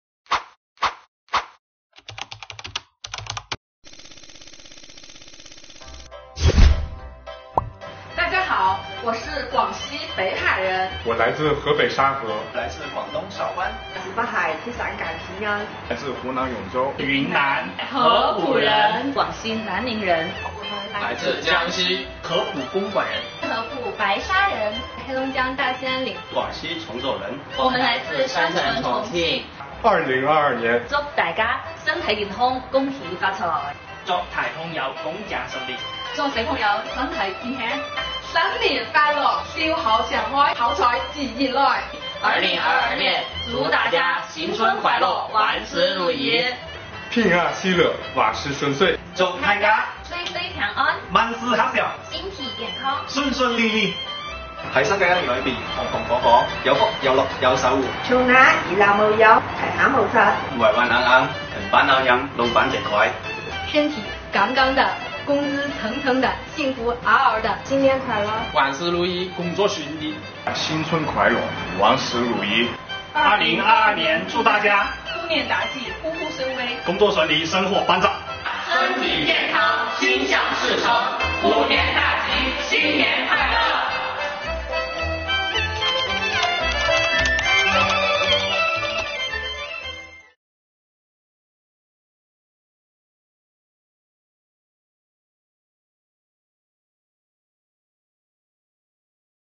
值此新春佳节之际广西各地税务干部用短视频向您送来最诚挚的新春祝福。